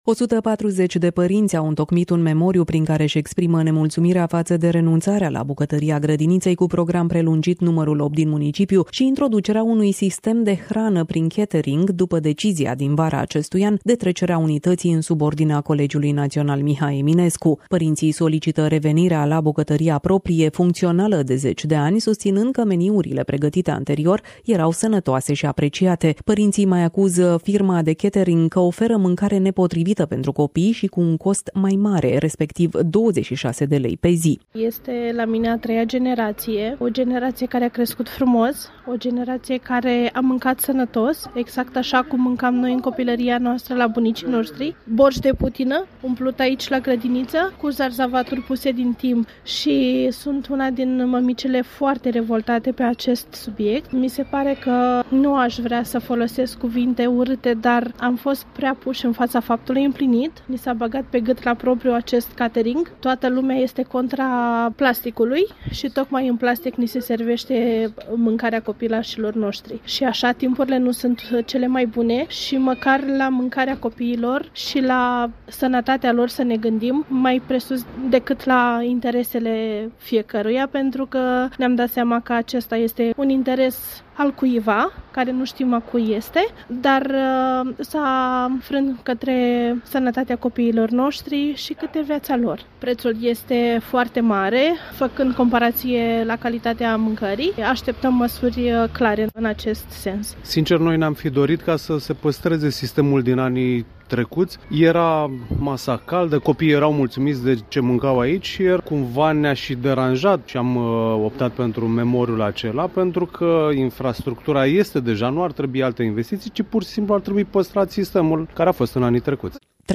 Varianta audio a reportajului: